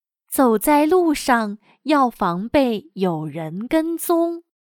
/Zǒu zài lǚo sháng yào fángbèi yǒu rén géng zōng./Evita ser seguido por alguien en el camino.